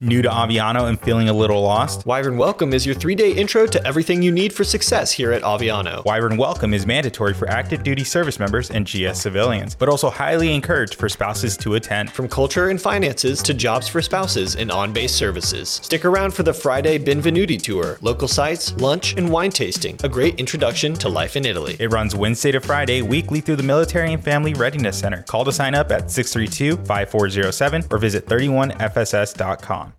AFN Aviano Radio Spot: Wyvern Welcome
A radio spot highlighting the Military & Family Readiness Center’s Wyvern Welcome workshop at Aviano Air Base, Italy. The MFRC provides services designed to assist service members and their families with finances, PCS moves, employment, volunteer opportunities, and deployment readiness, promoting mission readiness, community and wellness for Aviano’s newcomers.